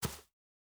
Shoe Step Grass Hard E.wav